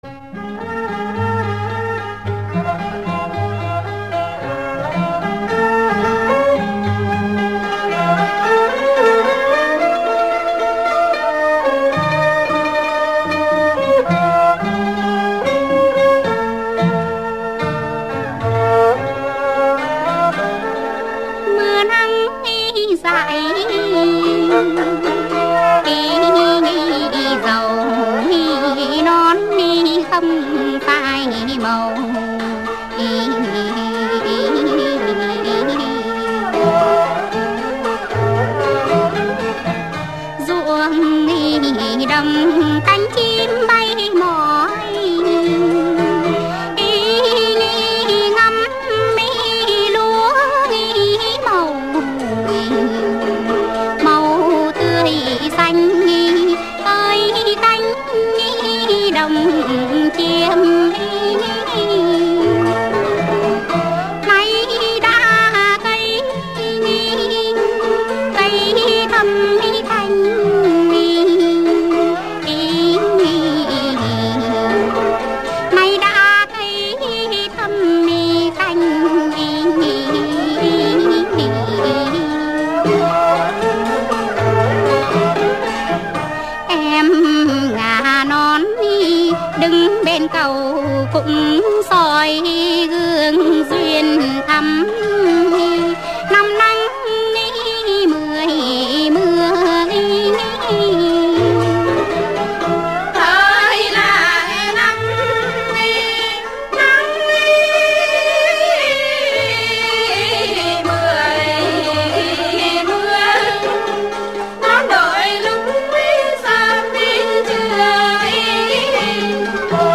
thuộc thể loại Hát chèo cổ.